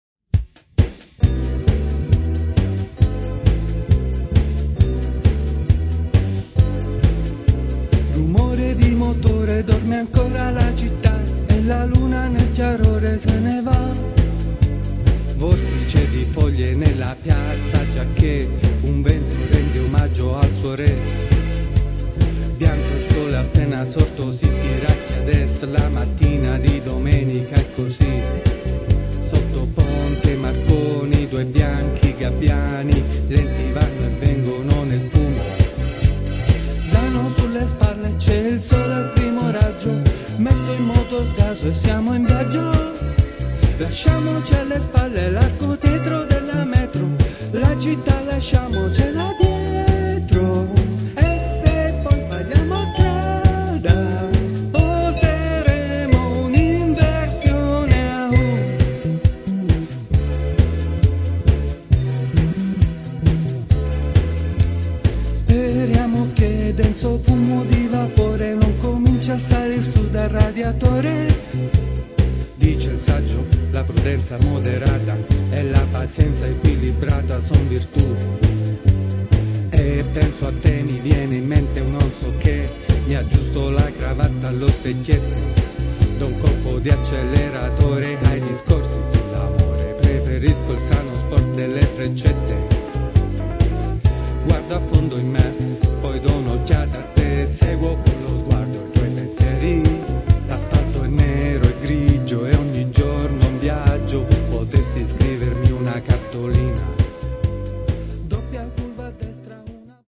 impegnati nel rock alternativo e nel rock dance
alternative rock and rock dance